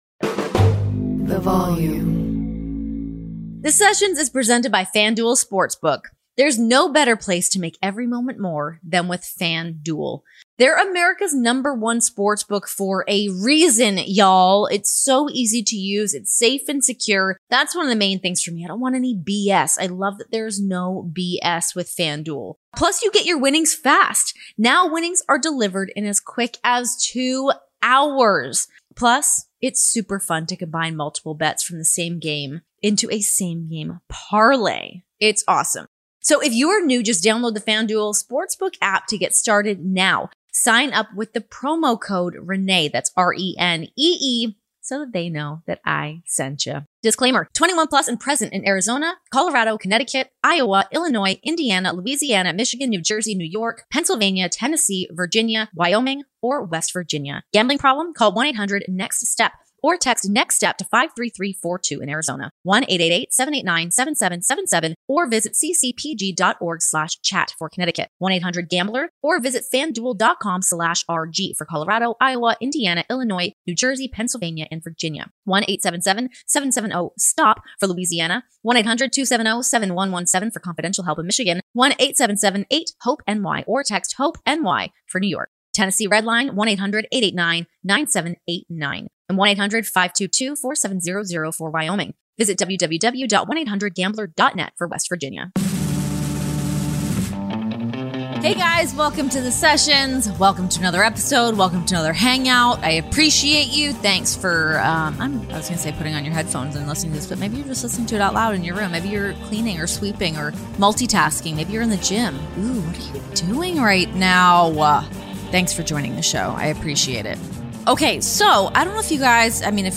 The UFC on ESPN analyst chats with Renee about what goes into producing an MMA show, barely missing the boat on the women’s MMA boom, and the advice she got from Joe Rogan.